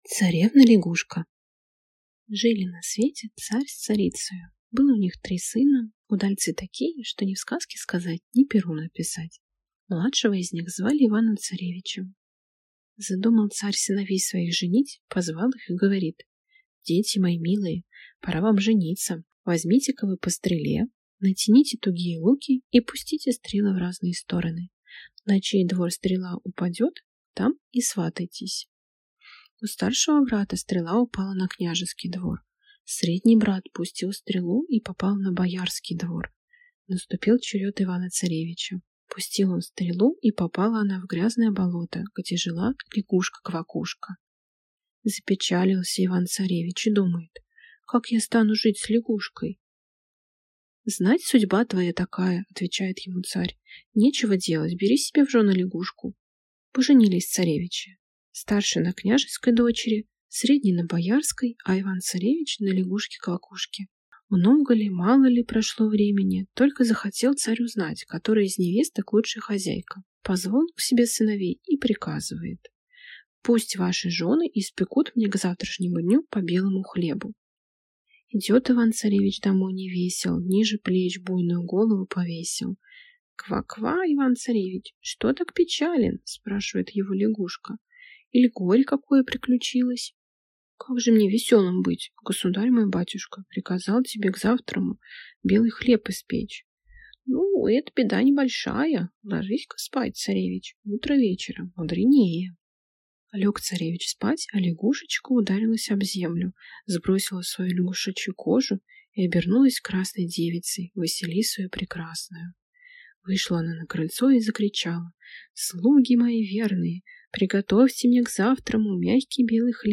Аудиокнига Царевна-лягушка. Волшебные сказки | Библиотека аудиокниг